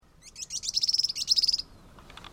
Canastero Coludo (Asthenes pyrrholeuca)
Condición: Silvestre
Certeza: Observada, Vocalización Grabada
Canastero-Coludo.mp3